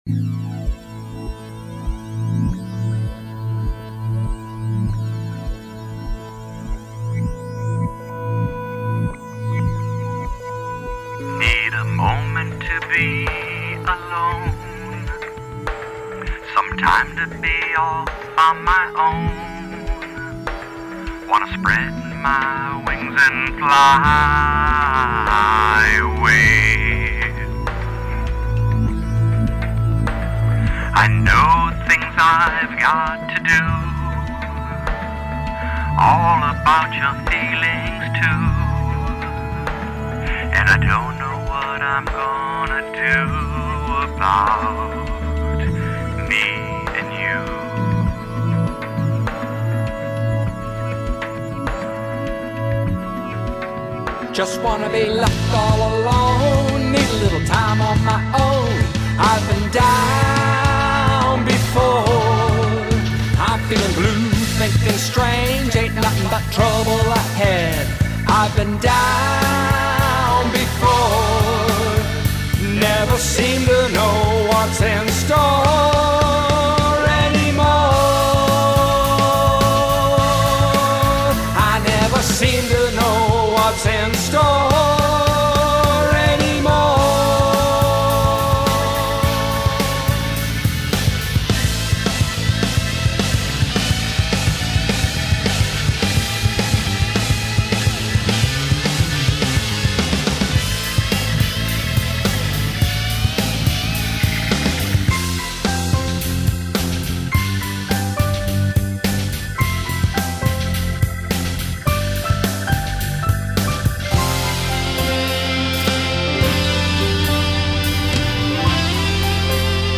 Orchestration recorded in New York City
Vocals recorded- Pacifica Studio, Cleveland, Ohio